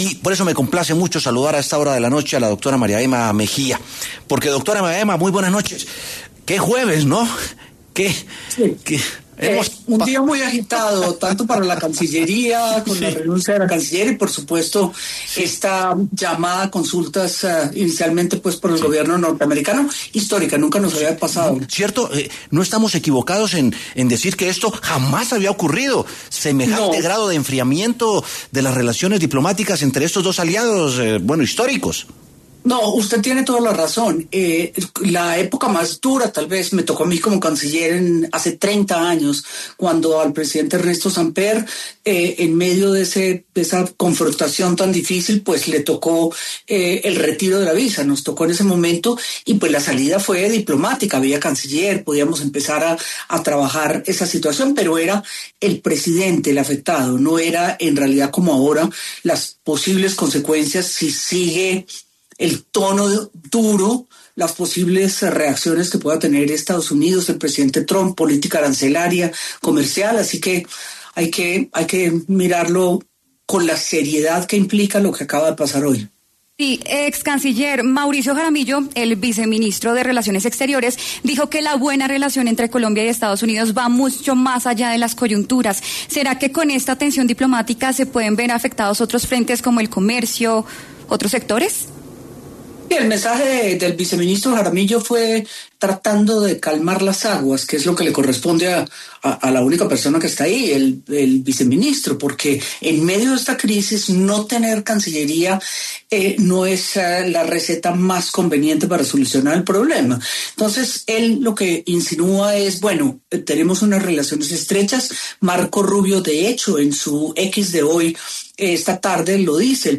María Emma Mejía, excanciller de Colombia, se refirió en W Sin Carreta a la tensión entre el país y Estados Unidos, y dijo, tras la renuncia de Sarabia al Ministerio de Relaciones Exteriores, que “en medio de esta crisis, no tener Cancillería no es la receta más conveniente para solucionar el problema”.